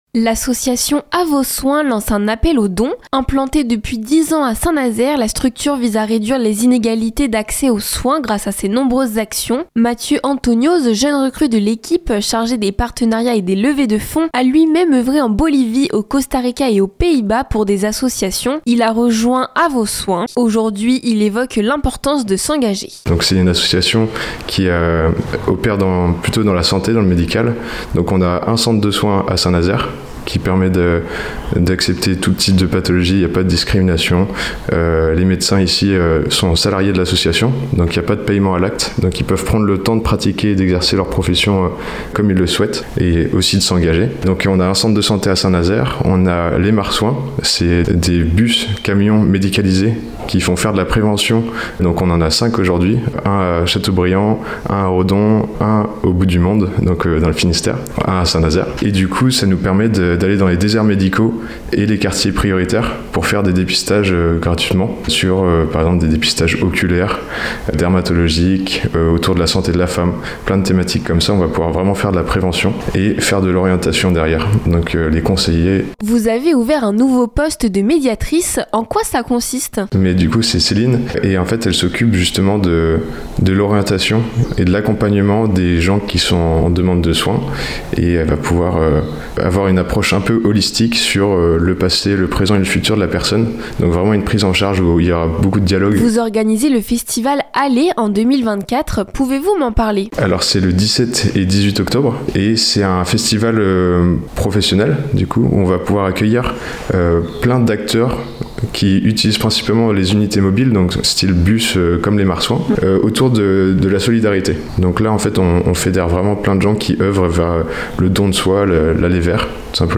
reportage-a-vos-soins_mixage-final.mp3